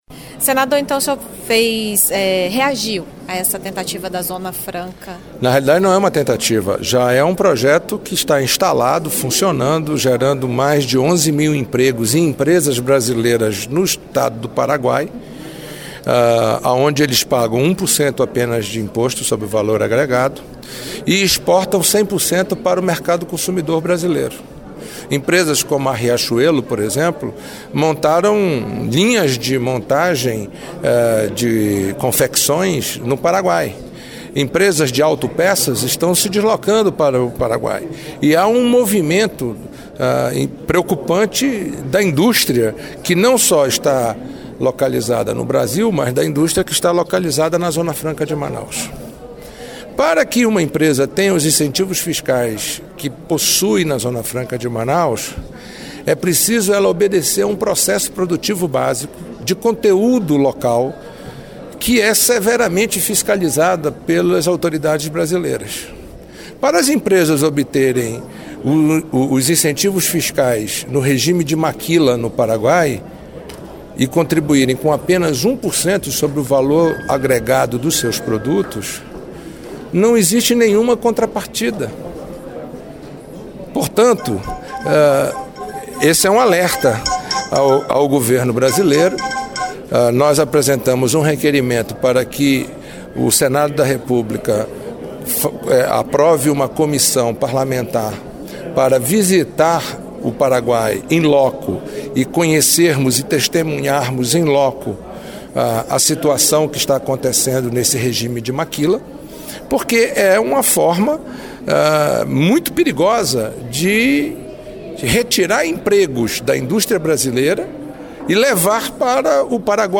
Em entrevista à Rádio Senado, o senador Eduardo Braga (PMDB-AM) pediu ao governo brasileiro que tome providências diante da criação da Zona Franca de Maquila, no Paraguai, que oferece isenção de impostos a empresas que se instalam naquele país para fabricar produtos destinados à exportação.